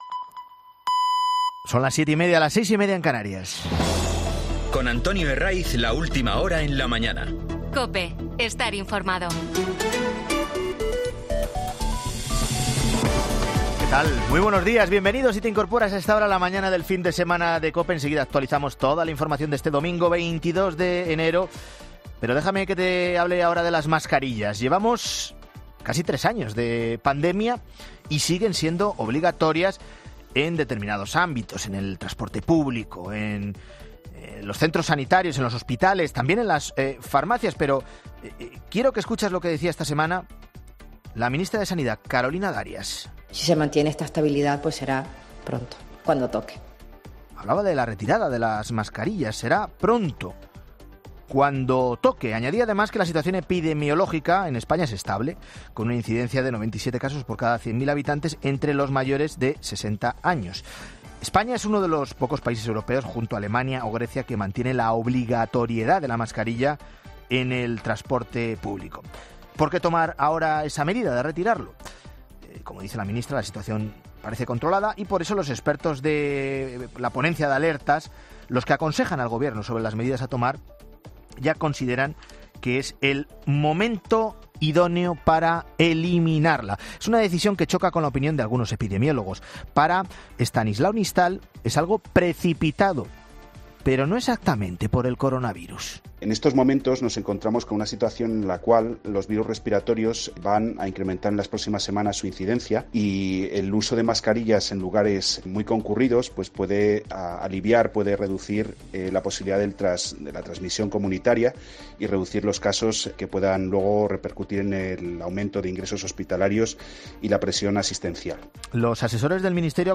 La Mañana Fin de Semana entrevista a expertos sobre el posible fin de las mascarillas en el transporte público